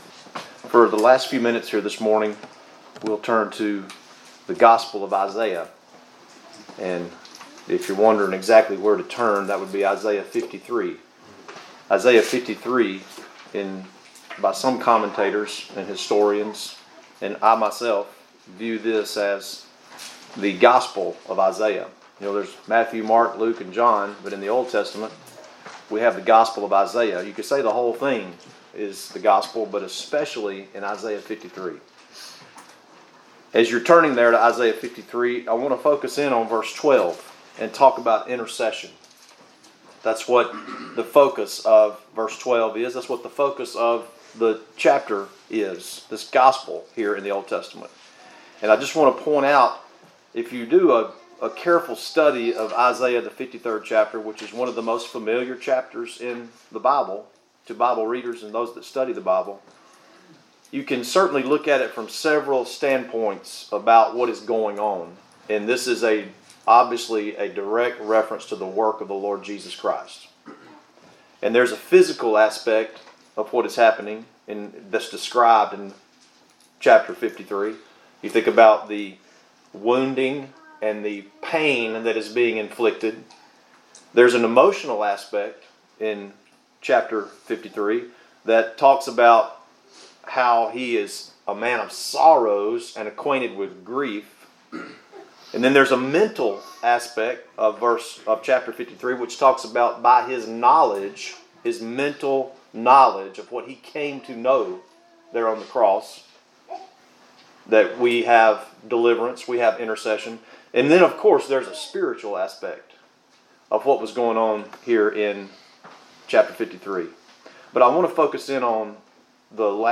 2025 Fellowship Meeting.